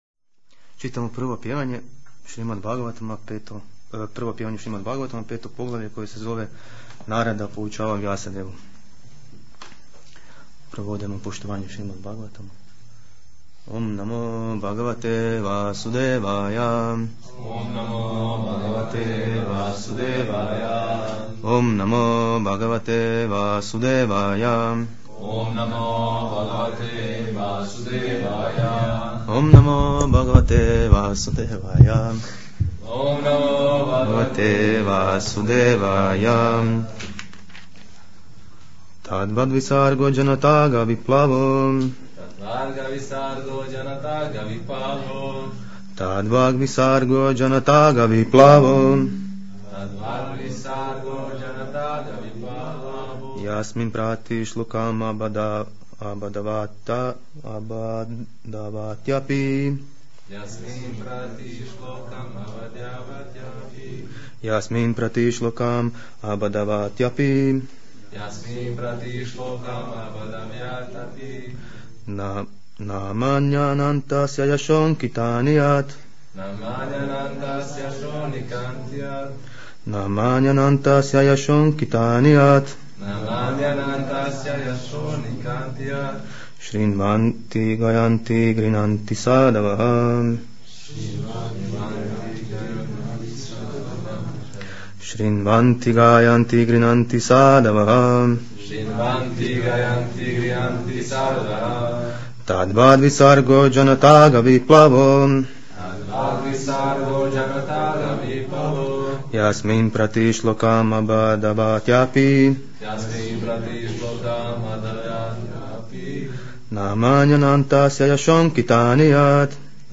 MP3 - Predavanja u ČK hramu
Na ovom mjestu možete pronaći snimke predavanja sa sunday feast programa i festivala u Čakovečkom centru.